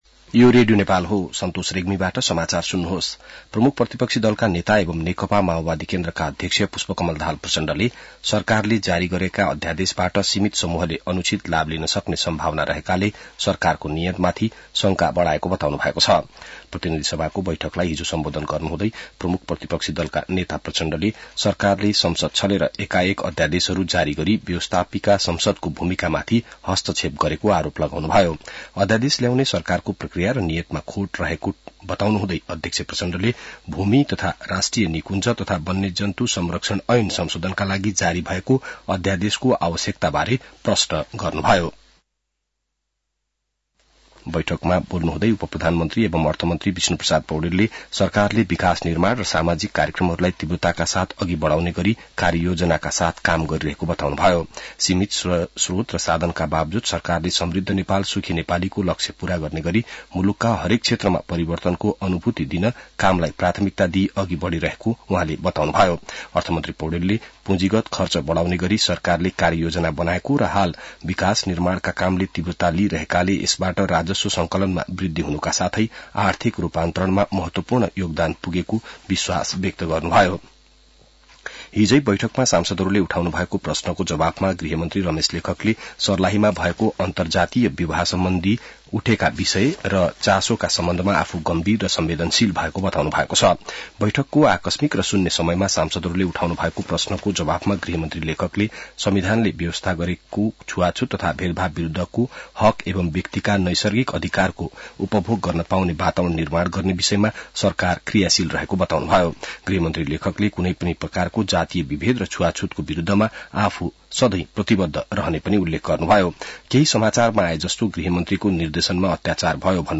बिहान ६ बजेको नेपाली समाचार : २६ माघ , २०८१